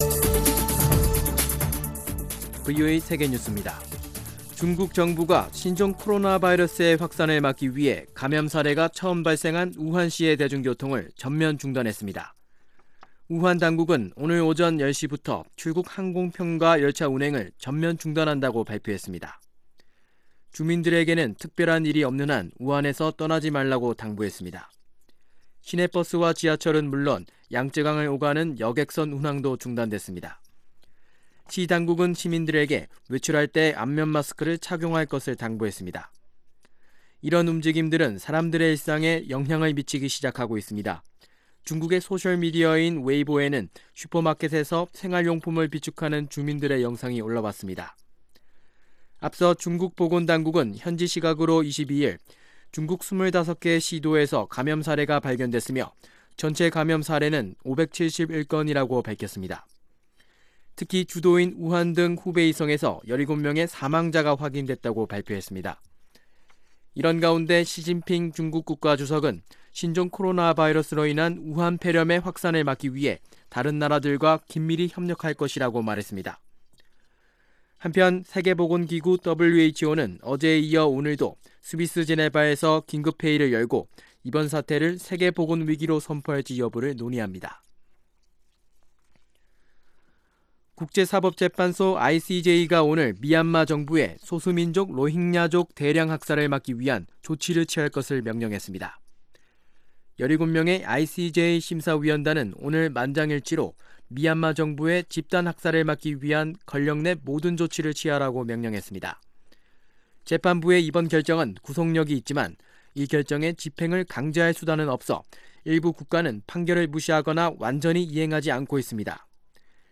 VOA 한국어 간판 뉴스 프로그램 '뉴스 투데이', 2020년 1월 23일 3부 방송입니다. 미 국무부 고위 관리는 대북 외교가 느린 속도로 진행되고 있다며, 북한에 대한 압박을 지속할 것이라고 밝혔습니다. 미 하원 군사위 민주당 측은 오는 28일로 예정된 ‘한반도 안보 현황 점검’ 청문회에서 북한의 위협과 미군 대비태세, 미-한 방위비 분담금 협상 현황을 집중적으로 다룰 것이라고 예고했습니다.